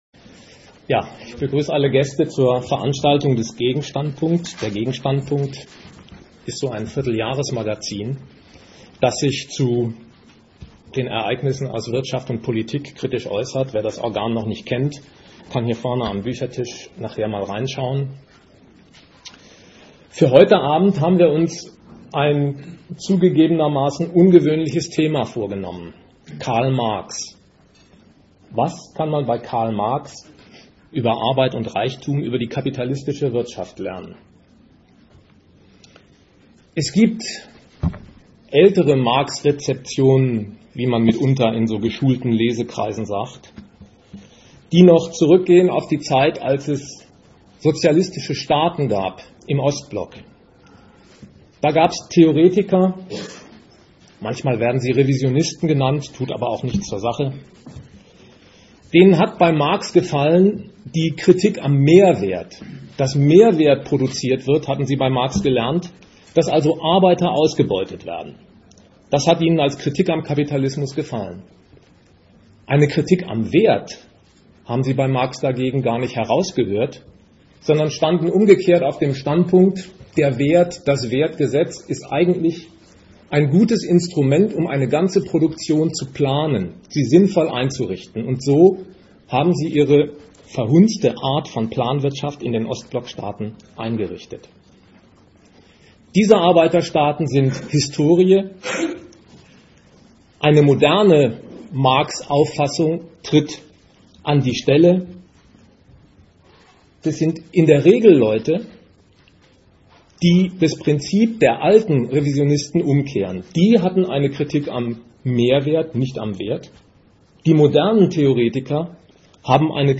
Seine Bücher helfen, die ökonomische Wirklichkeit heute zu erklären. Das will der Vortrag demonstrieren.
Gliederung: Teile 1 bis 3: Kapital, Band I Teil 4 und 5: Diskussion Das Thema existiert zwar schon zweimal, aber die Diskussion ist ausgiebig, wenn auch manchmal etwas leise aus dem Publikum.